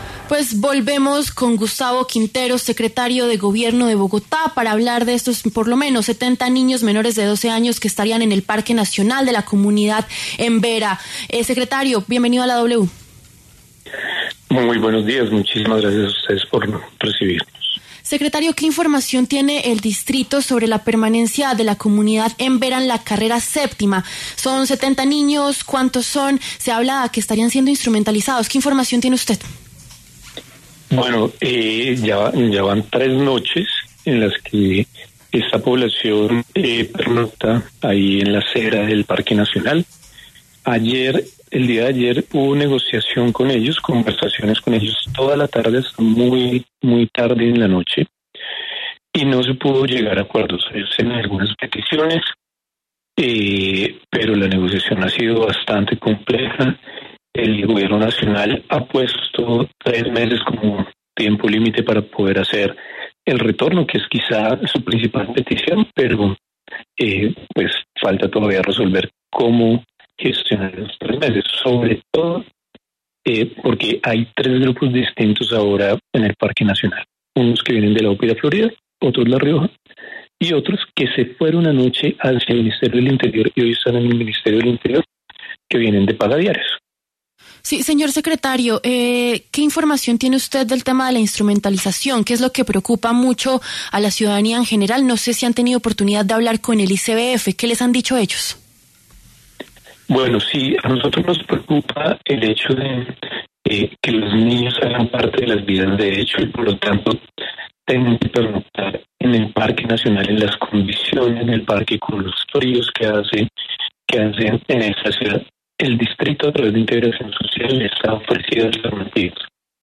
Por este motivo, en los micrófonos de W Fin De Semana, habló Gustavo Quintero, secretario de Gobierno de Bogotá para pronunciarse al respecto.